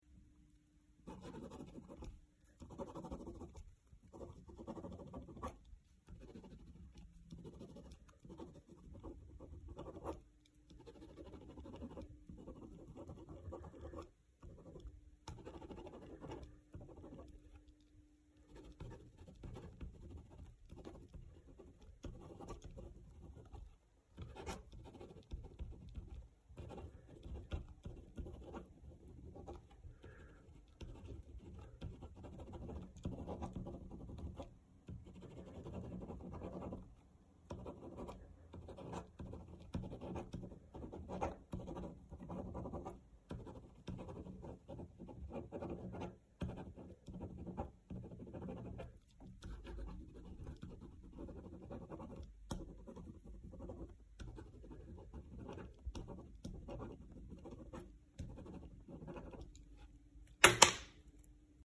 На этой странице собраны звуки пишущей ручки: от легкого постукивания по бумаге до равномерного скольжения стержня.
Пишут письмо и роняют ручку